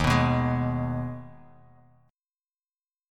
Ebsus4#5 chord